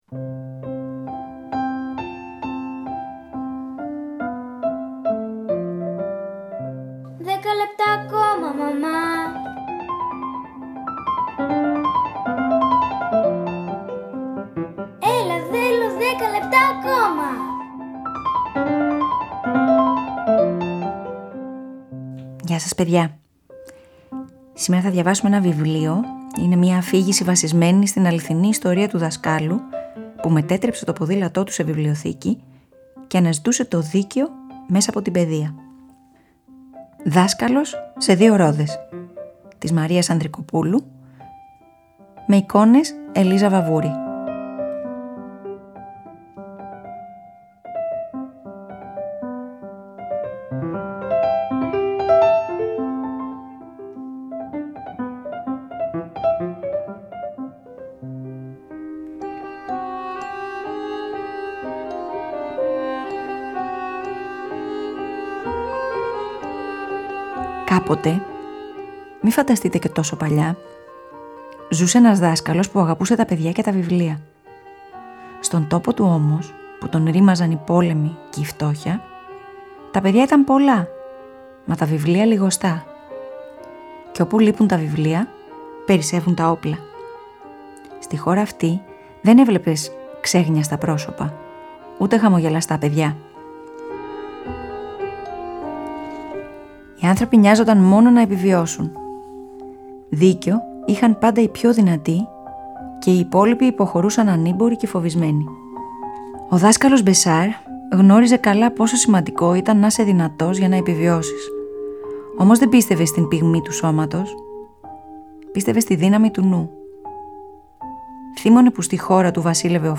Σήμερα θα διαβάσουμε μία ιστορία για την αγάπη ενός δασκάλου για τα βιβλία.